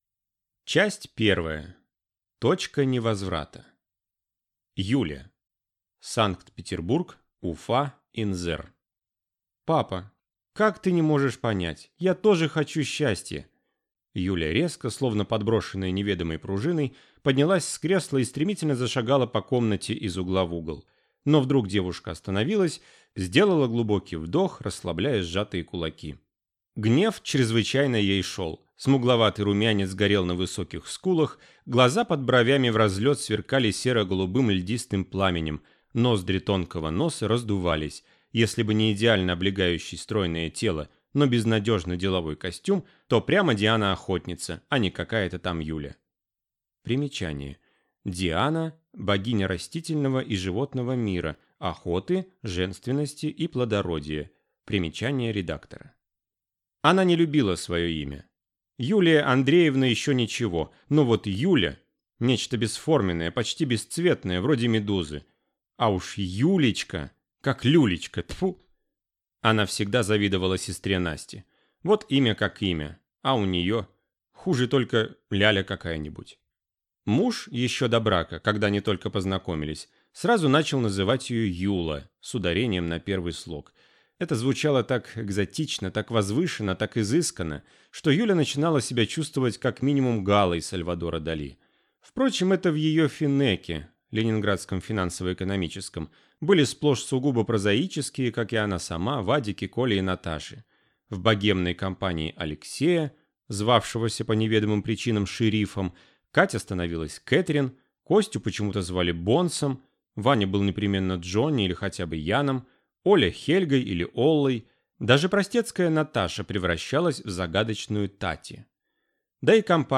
Аудиокнига Двойная жизнь - купить, скачать и слушать онлайн | КнигоПоиск